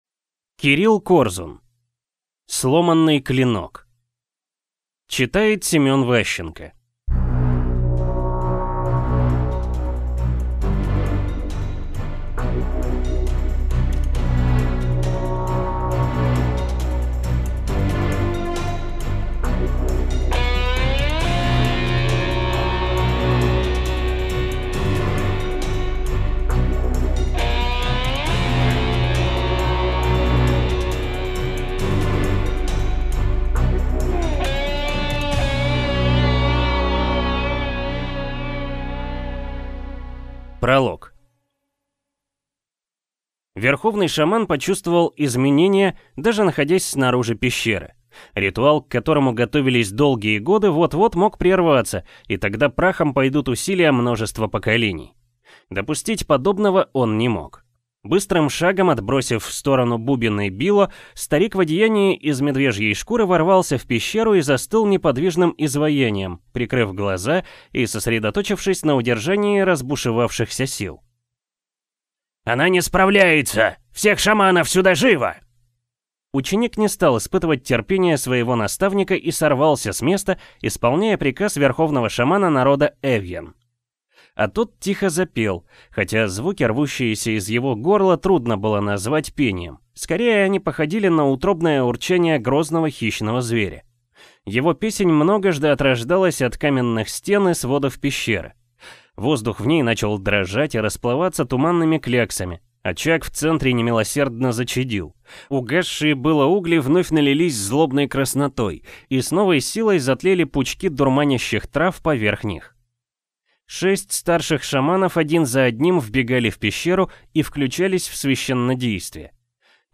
Аудиокнига Сломанный клинок | Библиотека аудиокниг
Прослушать и бесплатно скачать фрагмент аудиокниги